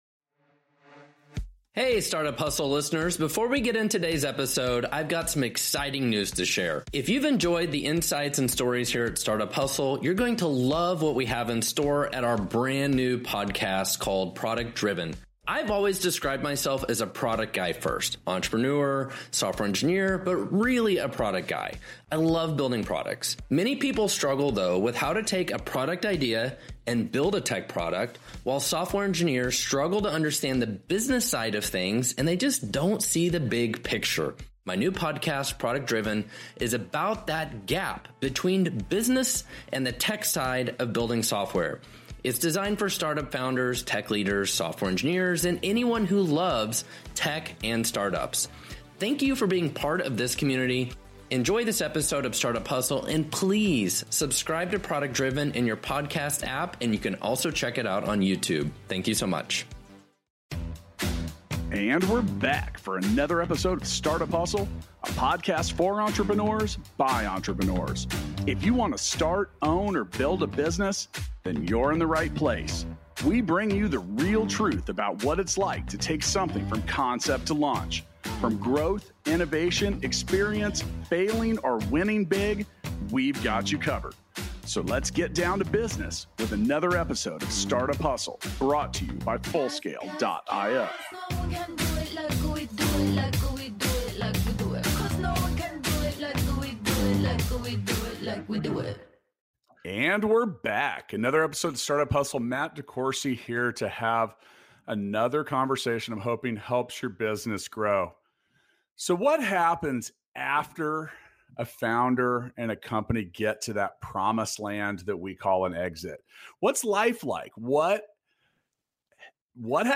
What does the approach pattern to a startup exit look like? What occurs after it? Listen and learn from their insightful conversation.